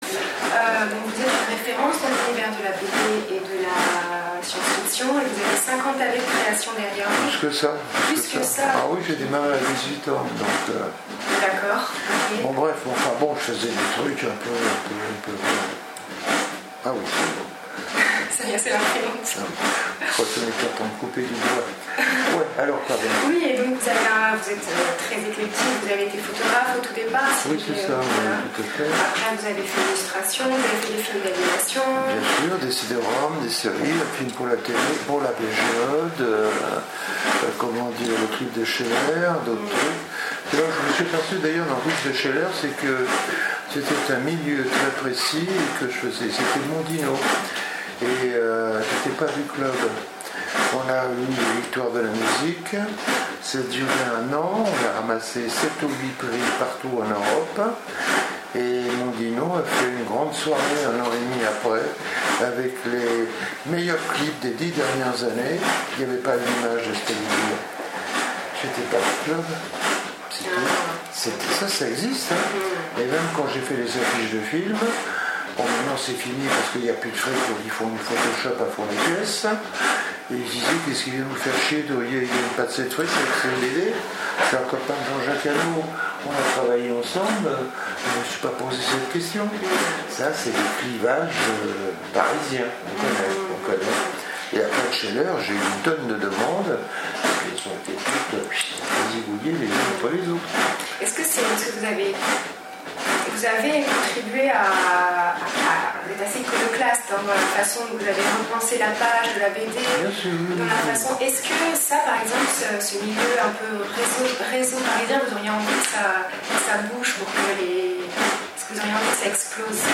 Interview 2017 : Philippe Druillet pour l’exposition Explorations
» C’est avec son franc-parler et la langue gouailleuse qui fait son sel que Philippe Druillet a résumé la raison d’être de cette exposition.